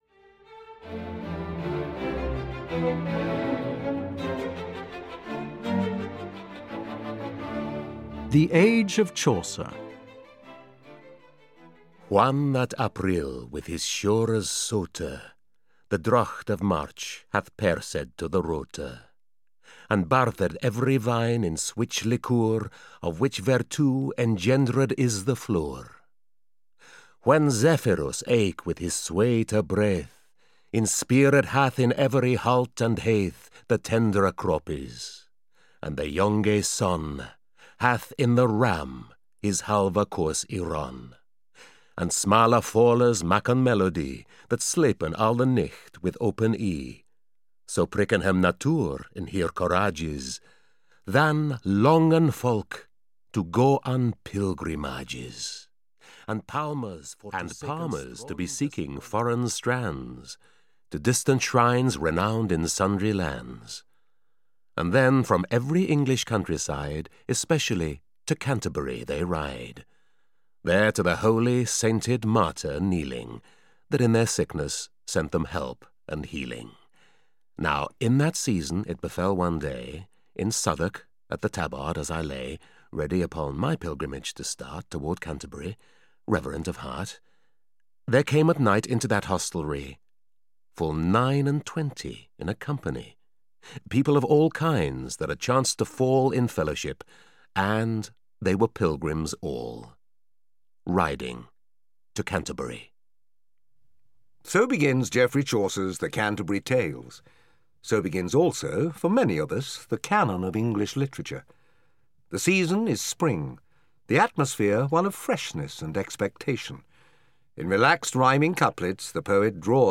The History of English Literature (EN) audiokniha
Ukázka z knihy